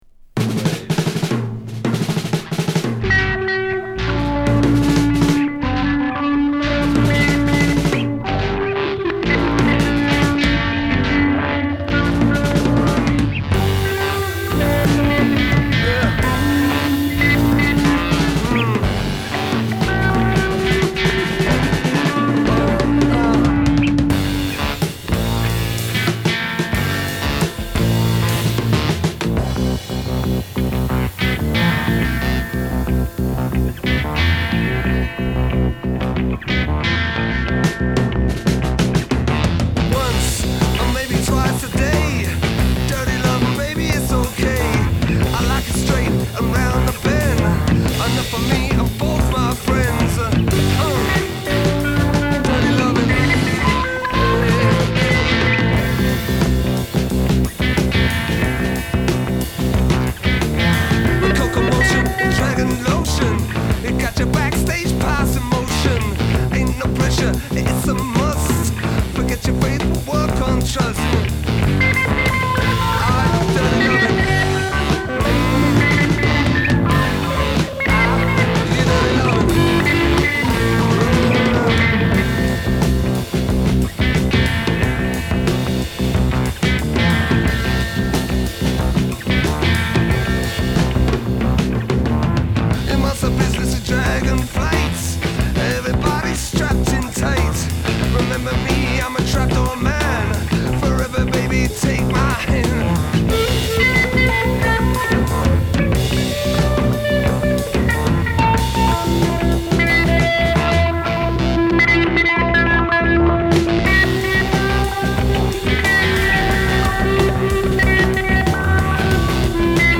超絶ダブ/スペーシーなアブストラクト・ロック